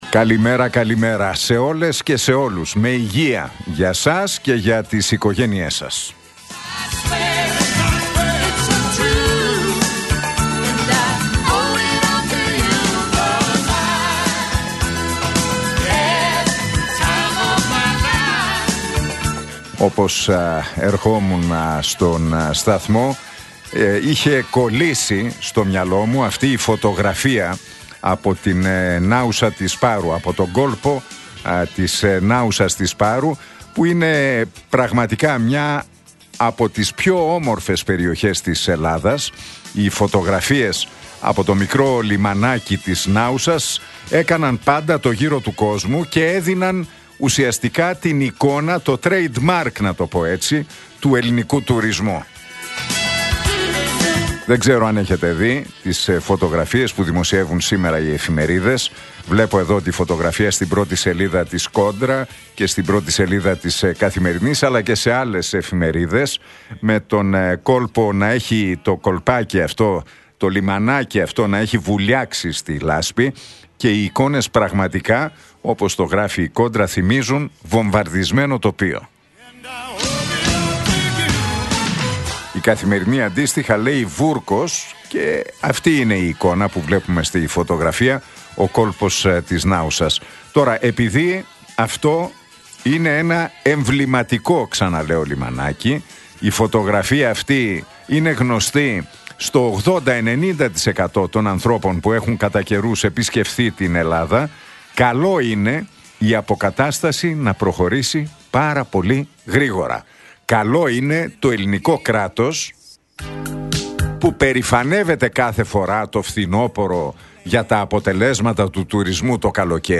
Ακούστε το σχόλιο του Νίκου Χατζηνικολάου στον ραδιοφωνικό σταθμό RealFm 97,8, την Τετάρτη 2 Απριλίου 2025.